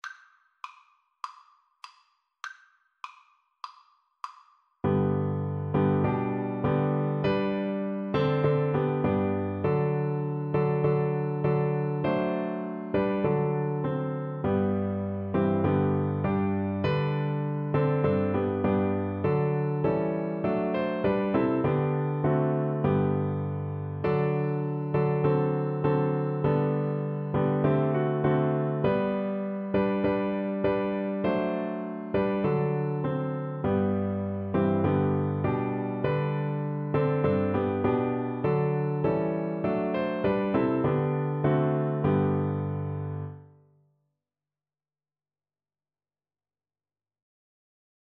Classical (View more Classical Cello Music)